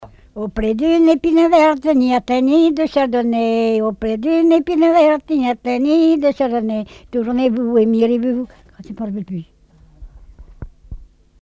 Divertissements d'adultes - Couplets à danser
danse : branle
Répertoire de chants brefs et traditionnels
Pièce musicale inédite